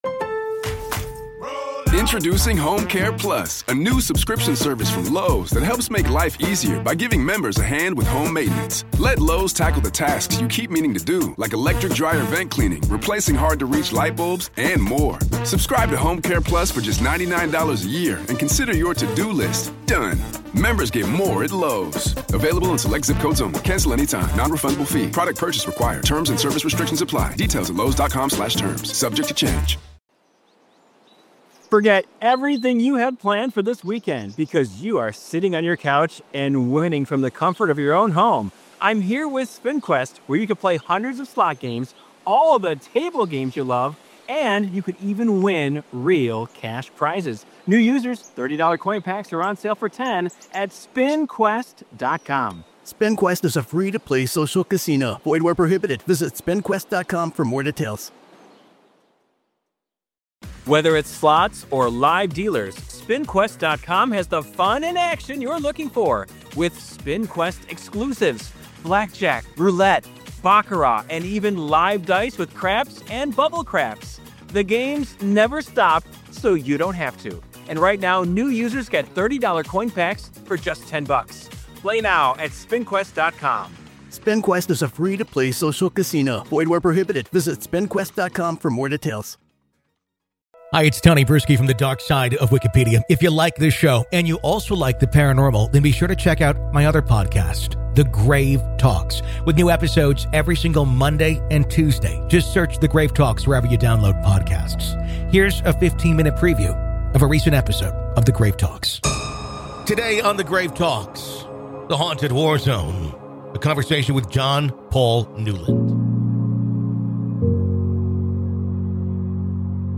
This would be far from the only spirit he would encounter. Today we hear his story, on The Grave Talks.